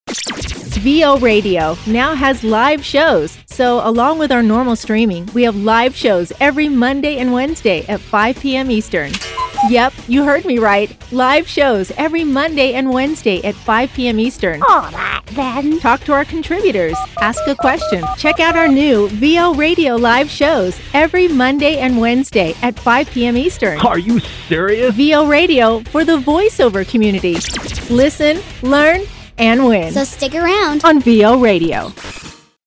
voradio-promo-live-shows1.mp3